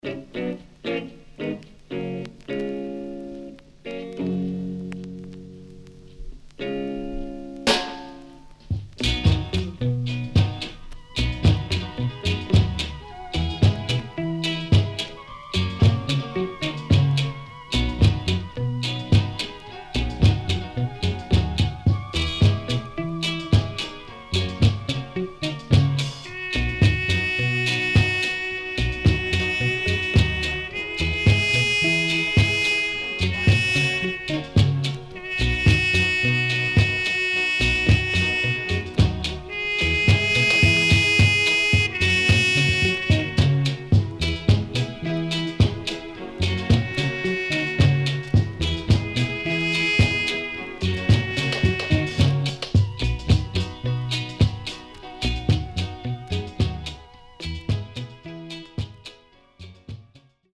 EARLY REGGAE
SIDE A:少しチリノイズ入ります。